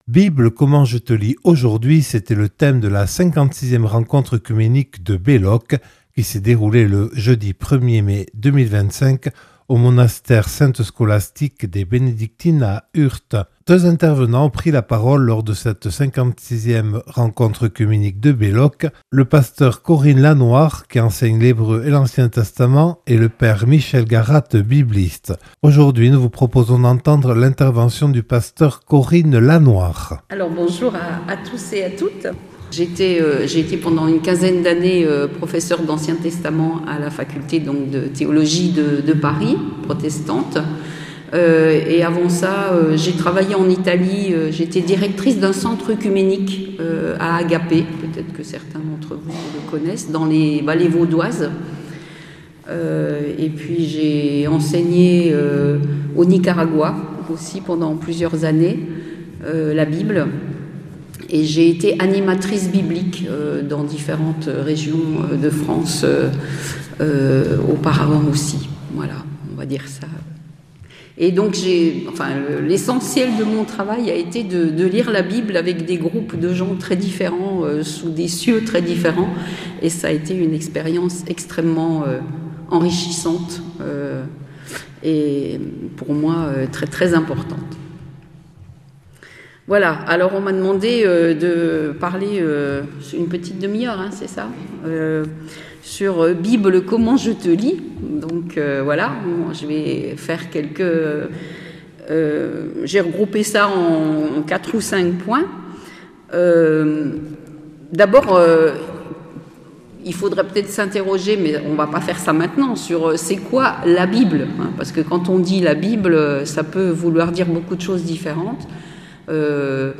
Intervention
(Enregistré le 01/05/2025 au monastère Sainte-Scholastique de Belloc à Urt).